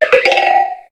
Cri de Pitrouille Taille Ultra dans Pokémon HOME.
Cri_0710_Ultra_HOME.ogg